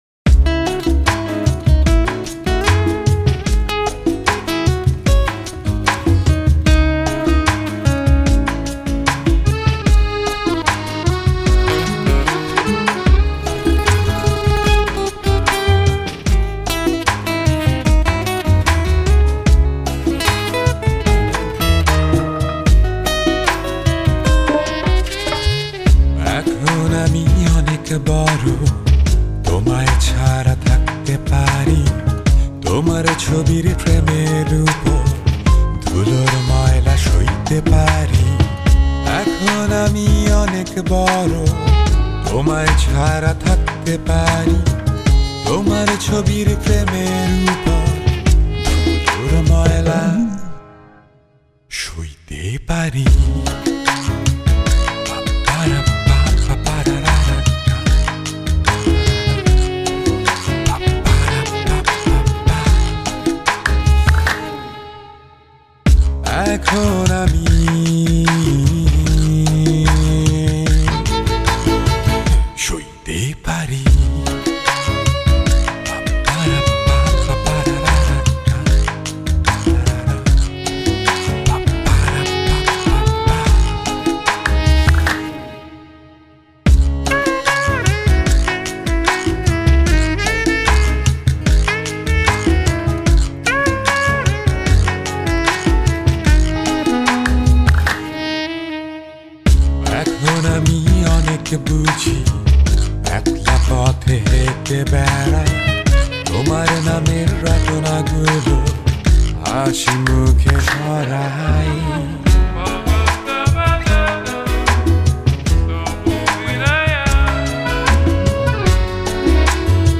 I am really liking the saxophone play in the songs.
28 Comments | Bangla Music | Tagged: , , , , | Permalink